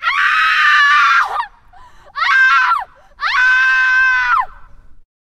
scream5.wav